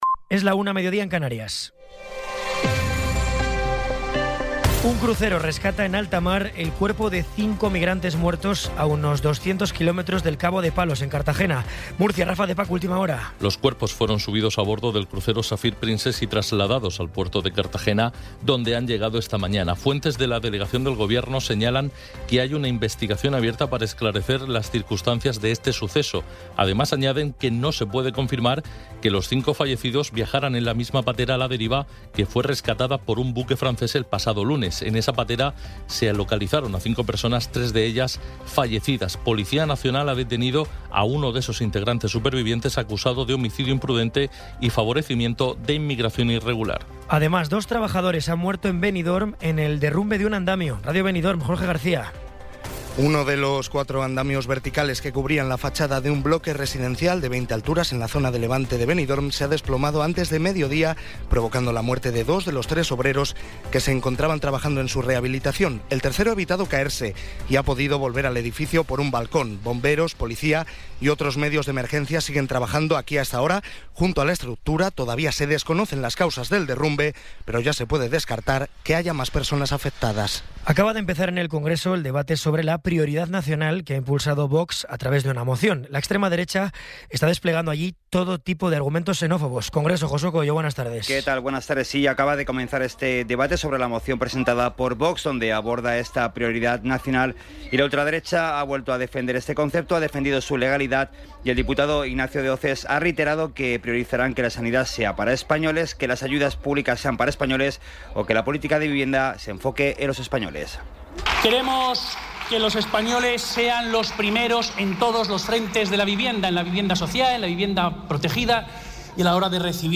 Resumen informativo con las noticias más destacadas del 22 de abril de 2026 a la una de la tarde.